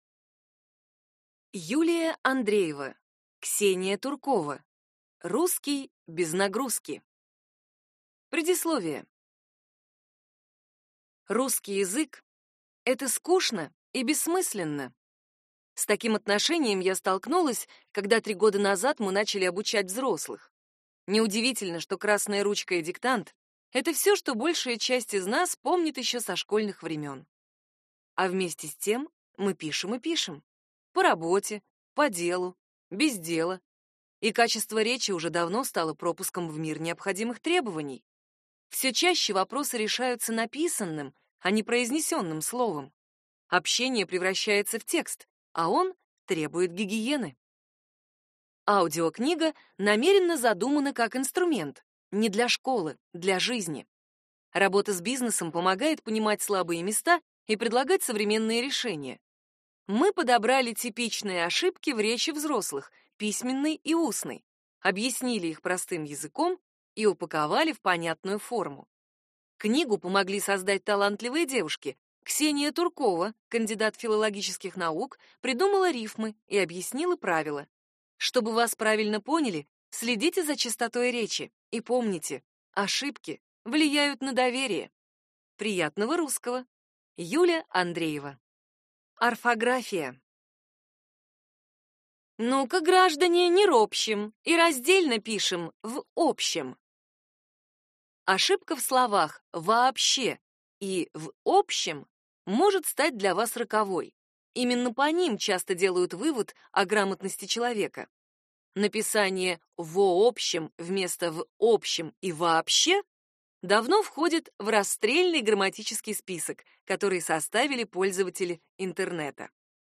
Аудиокнига Русский без нагрузки | Библиотека аудиокниг